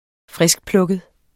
Udtale [ -plɔgəð ]